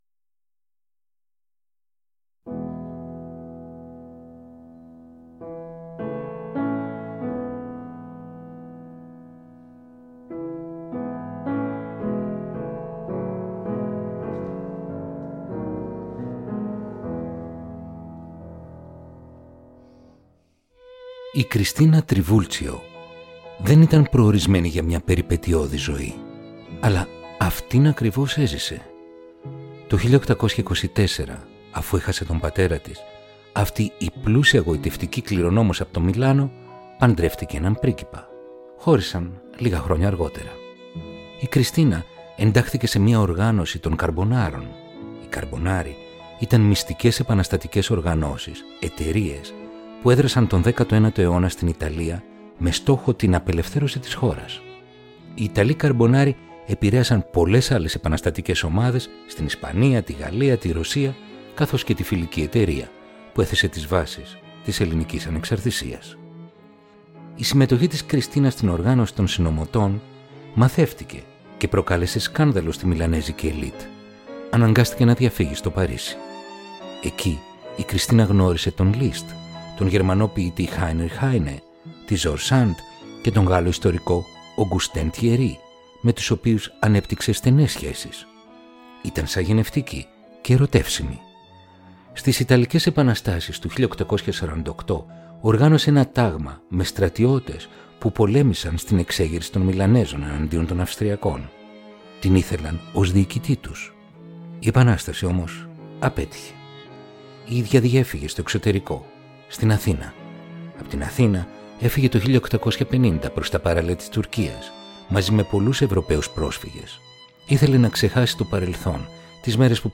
Ρομαντικά κοντσέρτα για πιάνο – Επεισόδιο 23ο